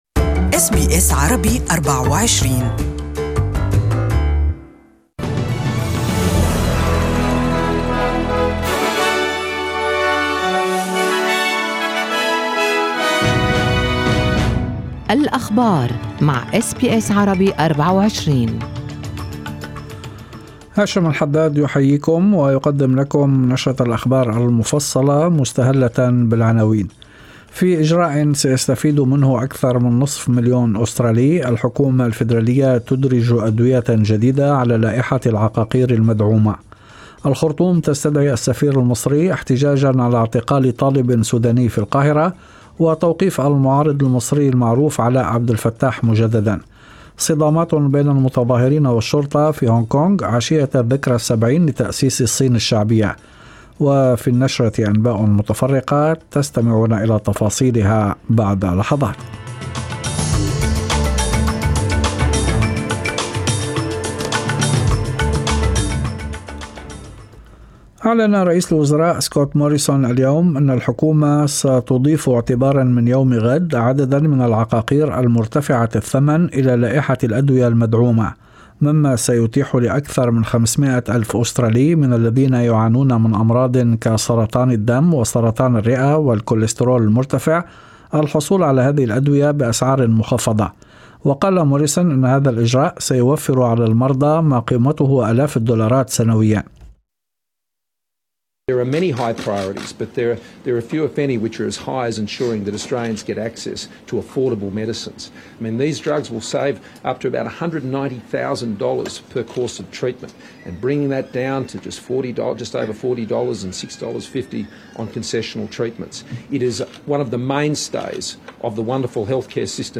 Evening News: Doctors and Nurses demand a fix for Aged Care